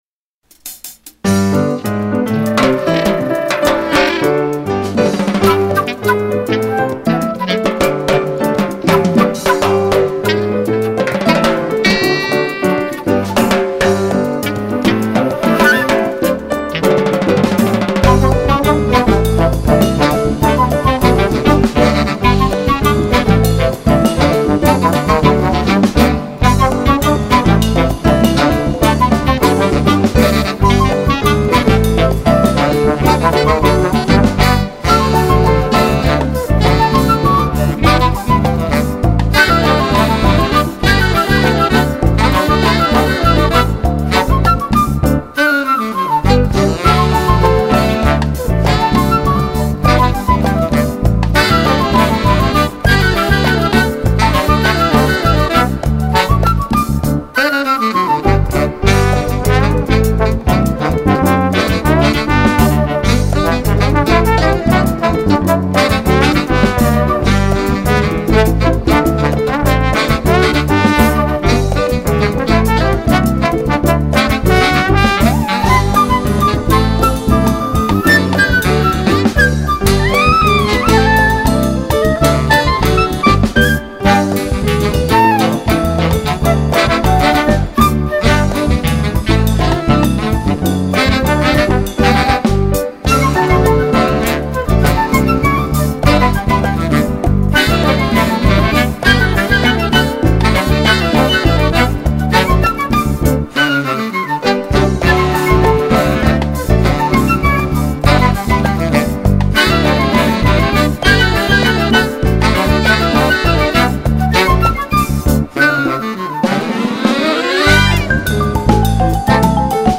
2199   03:13:00   Faixa:     Jazz
Bateria
Teclados
Clarinete
Flauta
Trombone de Vara